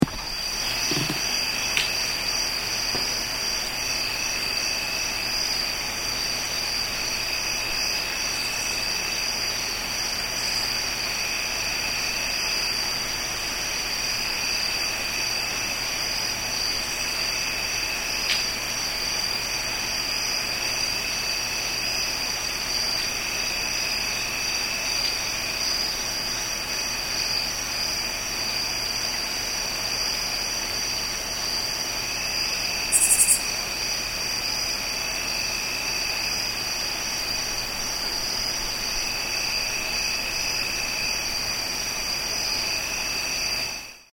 Crickets in Rural Pennsylvania
The sound of the night in rural Pennsylvania with crickets and other small, unseen creatures.